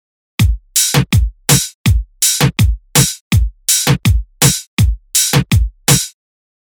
3：ハイハット・オープン使用
ハイハット・オープンを使うと、「1」「2」とはまた違った雰囲気になります。
reggaeton4.mp3